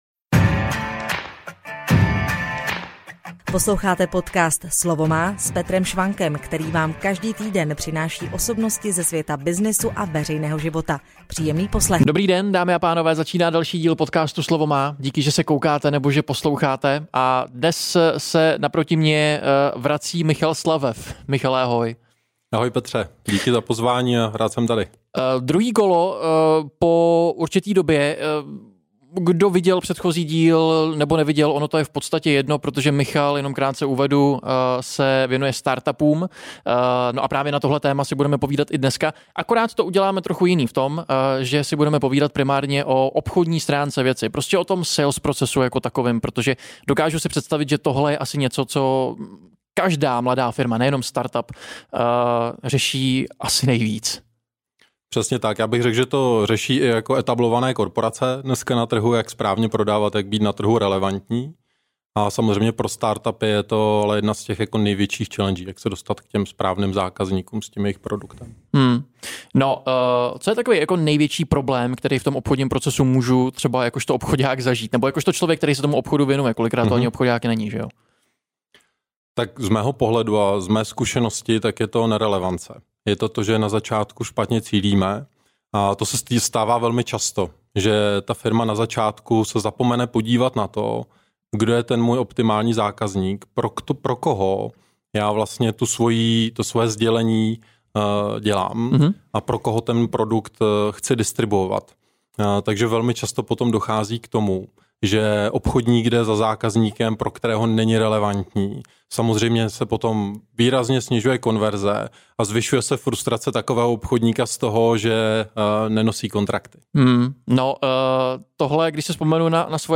Tzv. challenger sales chápe obchod trochu jinak. A jak, to si poslechněte v rozhovoru.